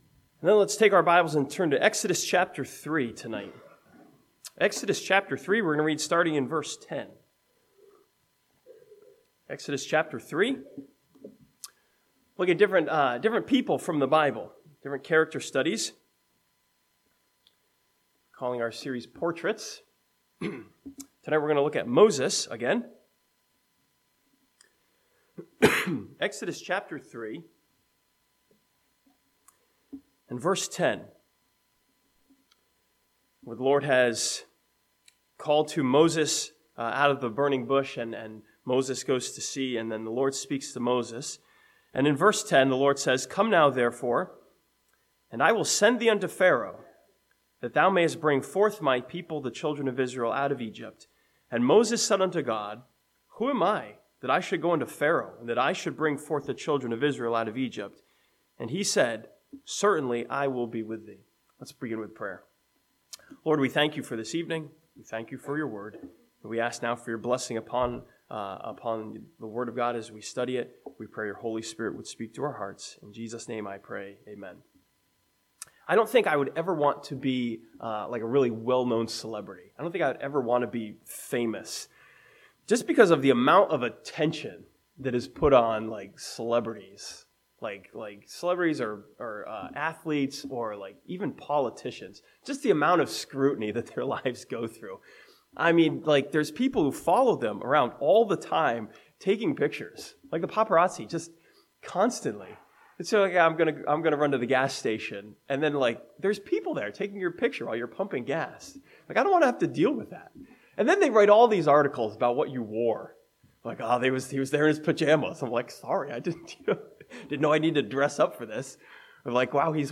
This sermon from Exodus chapters 3 and 4 looks at Moses as a portrait of insecurity and sees how God deals with him.